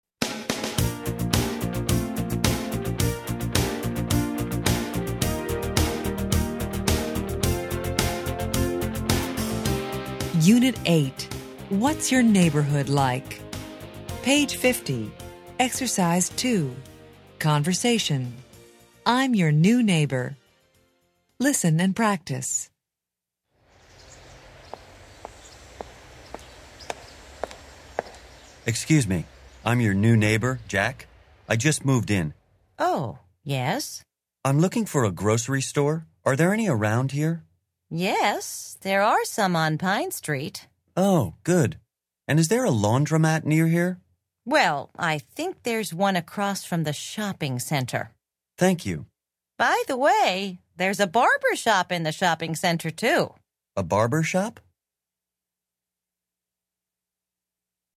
American English
Interchange Third Edition Level 1 Unit 8 Ex 2 Conversation Track 23 Students Book Student Arcade Self Study Audio